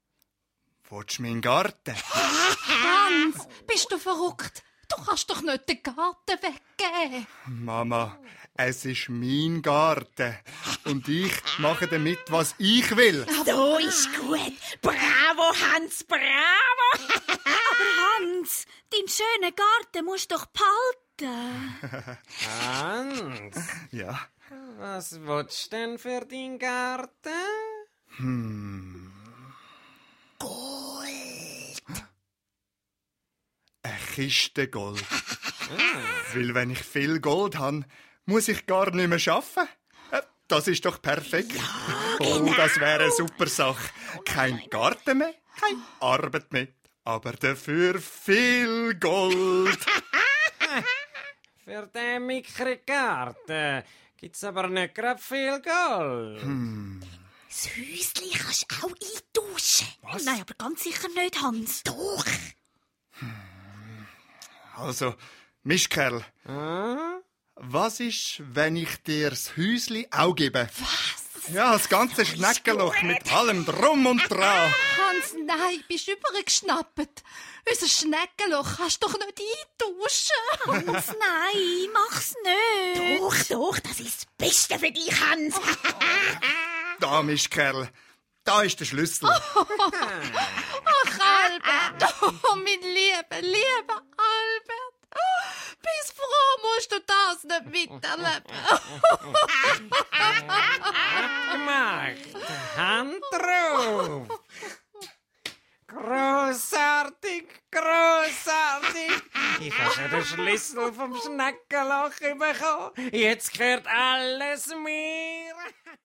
Ravensburger De Hans im Schnäggeloch ✔ tiptoi® Hörbuch ab 4 Jahren ✔ Jetzt online herunterladen!